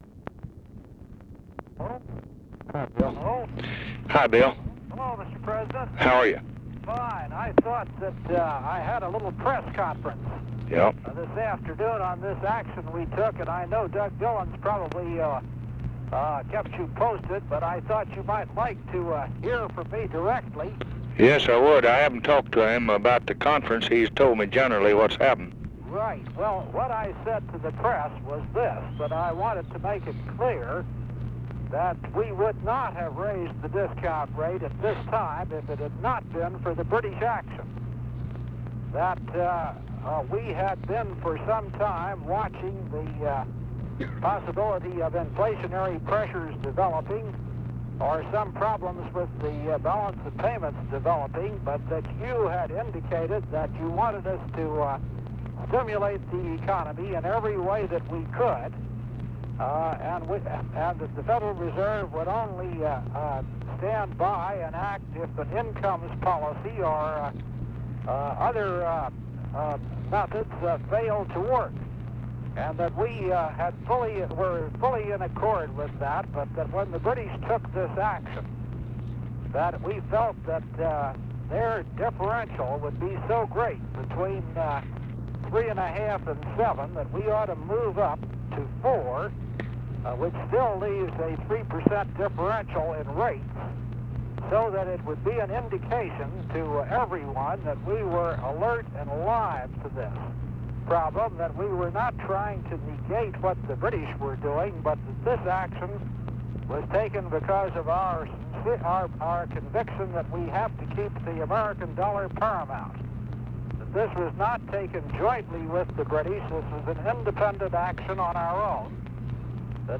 Conversation with WILLIAM MCC. MARTIN, November 24, 1964
Secret White House Tapes | Lyndon B. Johnson Presidency Conversation with WILLIAM MCC.